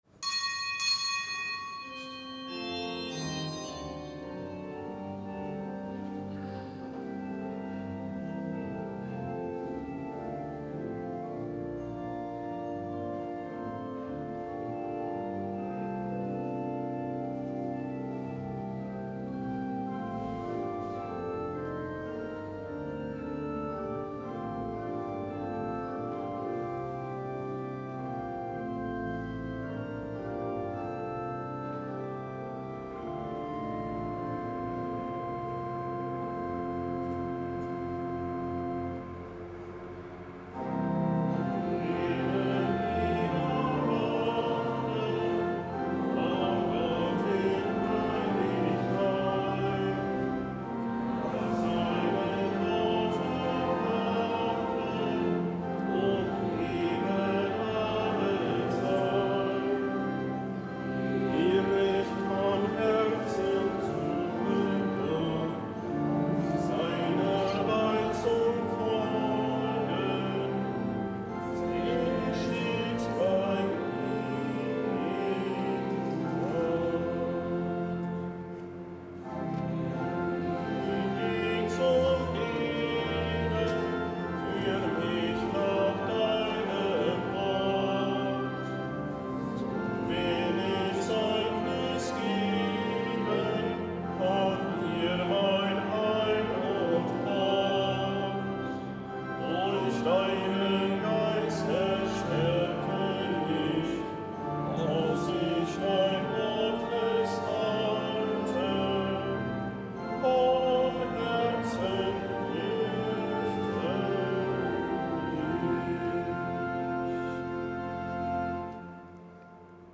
Kapitelsmesse am Gedenktag des Heiligen Alfons Maria von Liguori
Kapitelsmesse aus dem Kölner Dom am Gedenktag des Heiligen Alfons Maria von Liguori, Ordensgründer, Bischof, Kirchenlehrer Herz-Jesu Freitag.